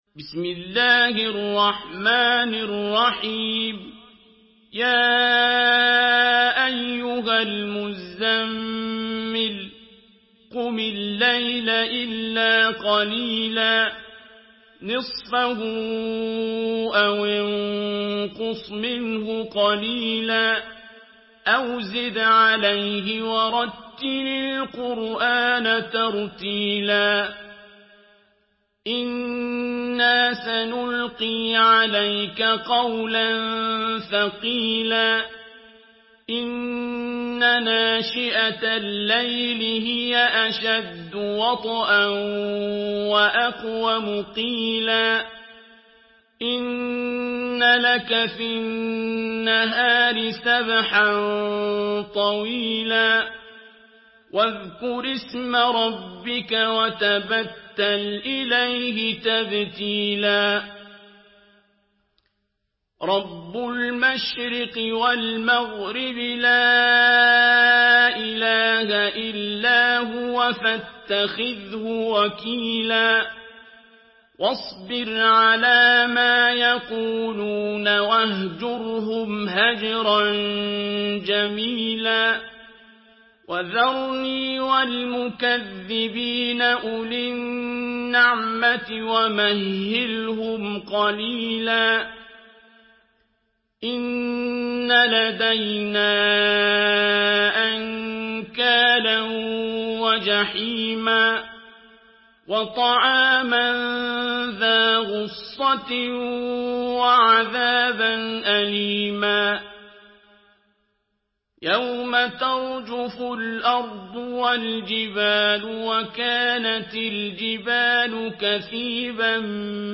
Surah Müzemmil MP3 in the Voice of Abdul Basit Abd Alsamad in Hafs Narration
Murattal Hafs An Asim